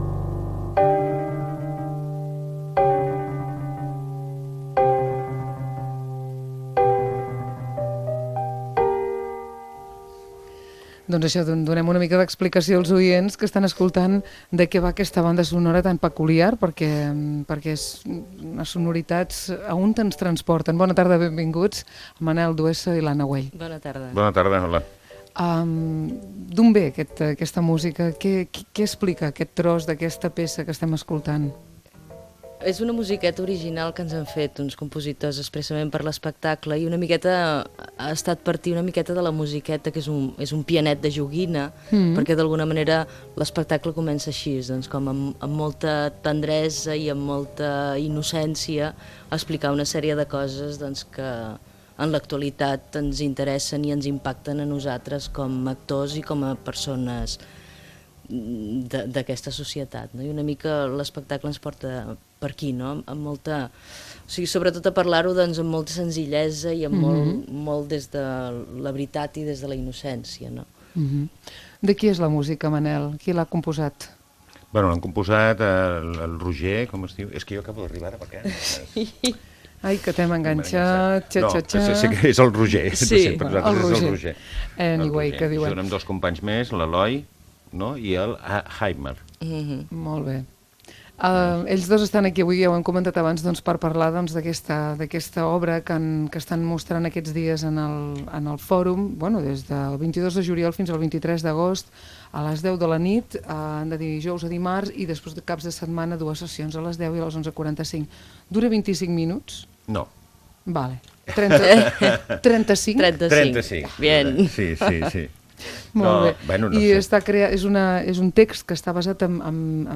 Fragment extret de l'arxiu sonor de COM Ràdio